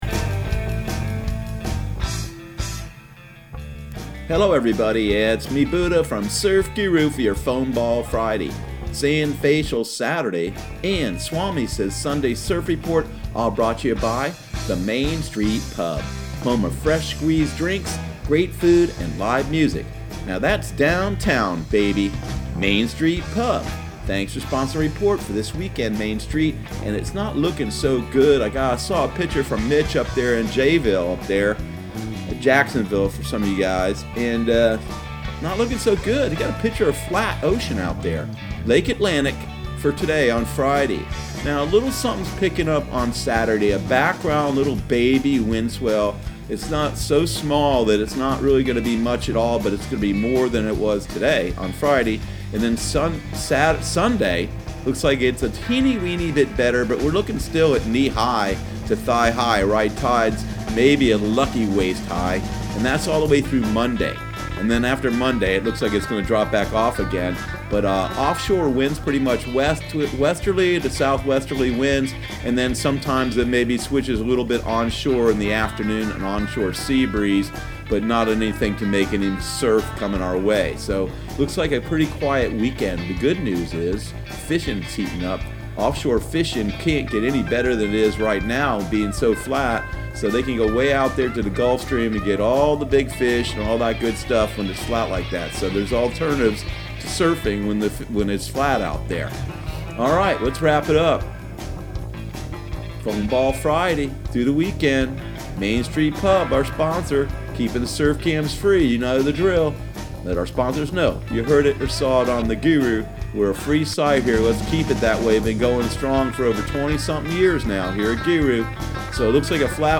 Surf Guru Surf Report and Forecast 06/21/2019 Audio surf report and surf forecast on June 21 for Central Florida and the Southeast.